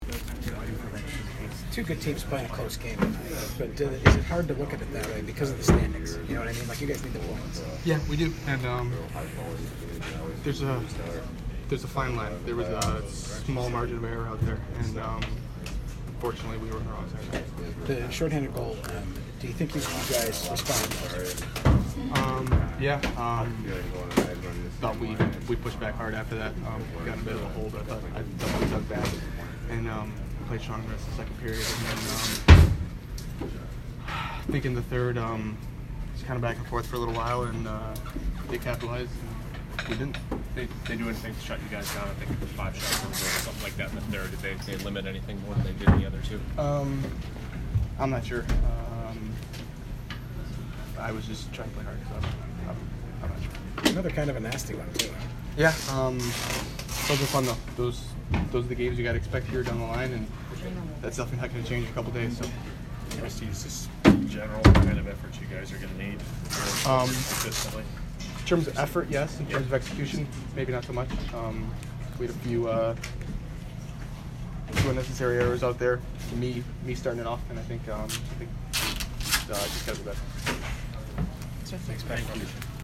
Bryan Rust post-game 2/9